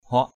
huak.mp3